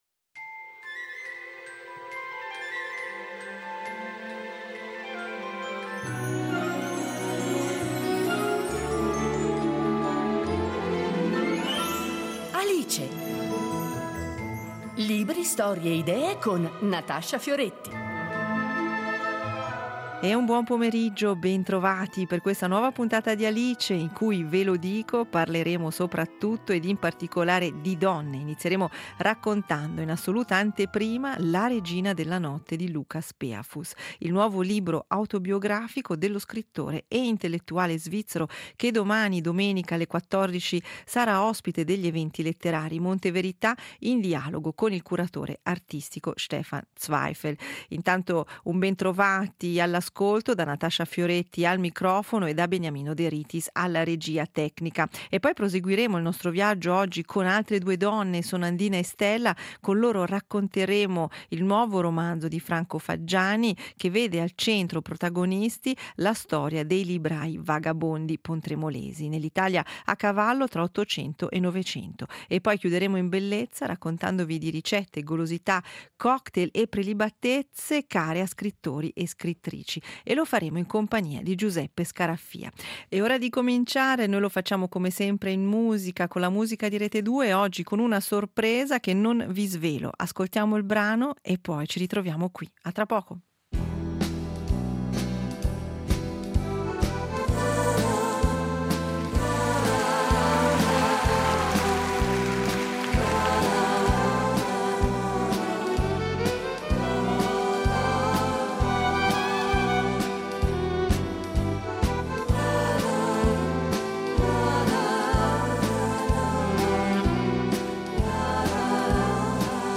Intervista allo scrittore e drammaturgo svizzero vincitore del premio Georg Büchner